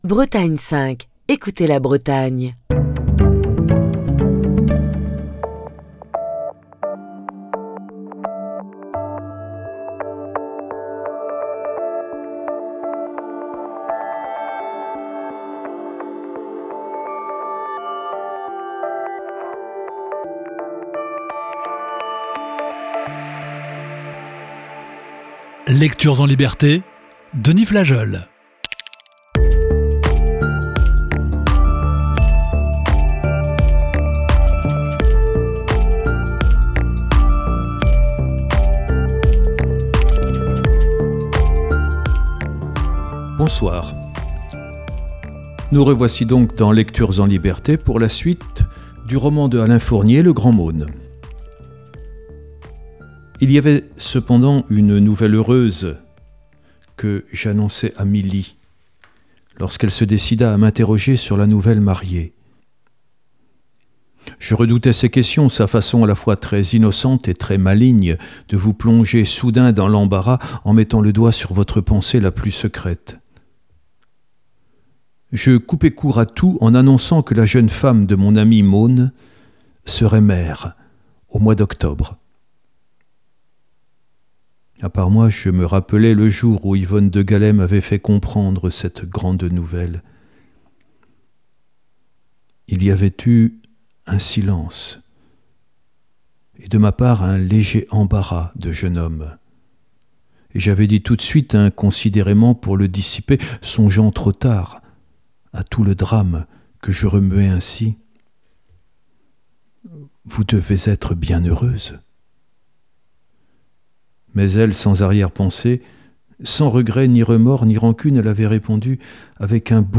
lit un classique de la littérature